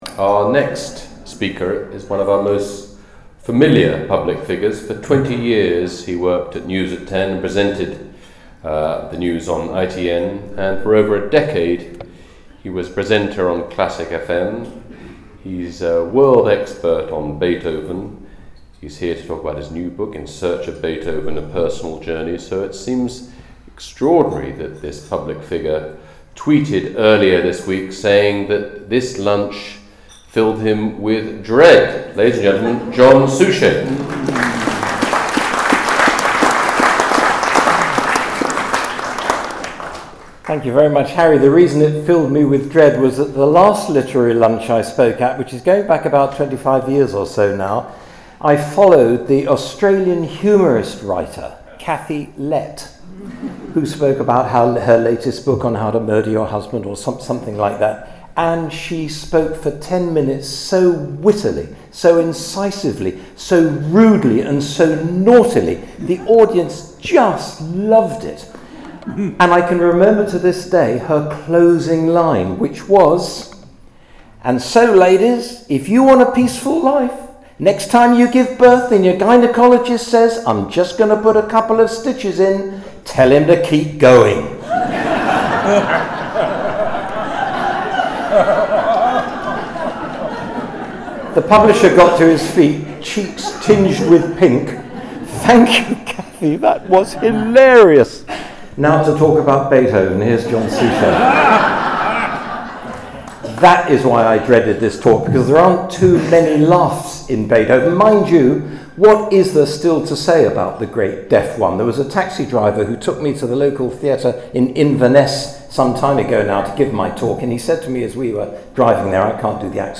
The Oldie Literary lunch was held at The National Liberal Club on 12th November 2024, sponsored by Kirker Holidays.